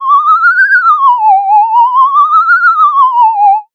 haunted-ambient-sound